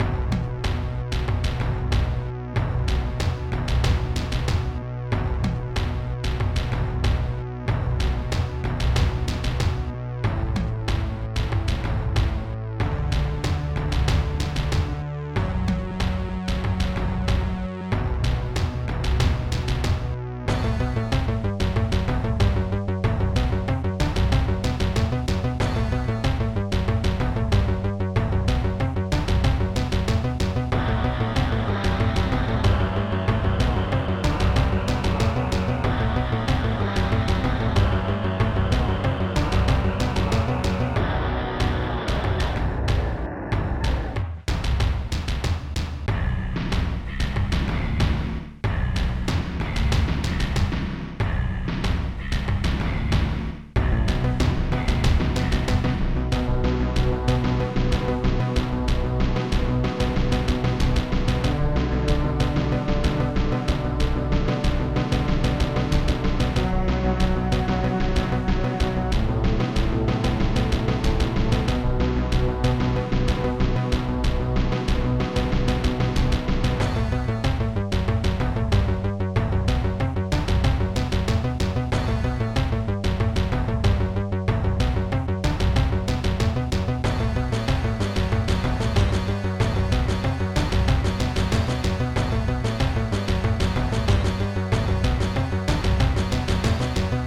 Technobass